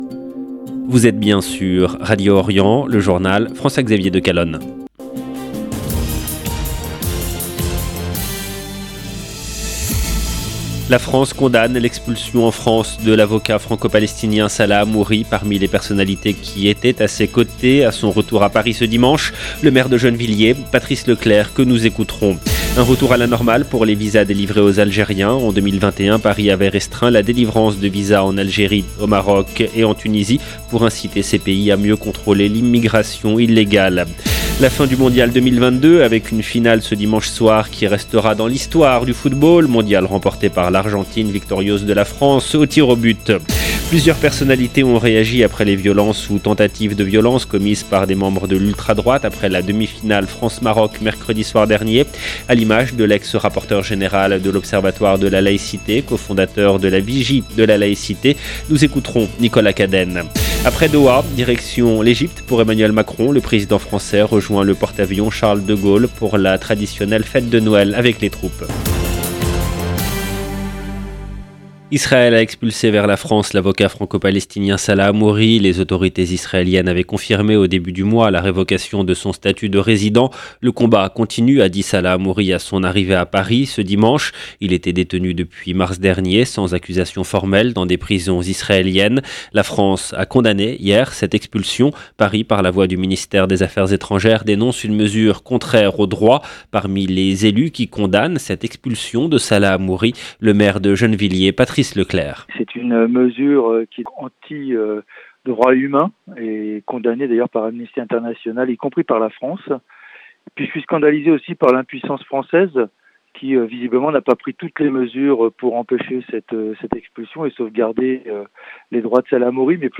17 min 33 sec LE JOURNAL EN LANGUE FRANCAISE DE MIDI DU 19/12/22 LB JOURNAL EN LANGUE FRANÇAISE La France condamne l’expulsion en France de l’avocat Franco palestinien Salah Hamouri. Parmi les personnalités qui étaient à ses côtés à son retour à Paris, le maire de Gennevilliers.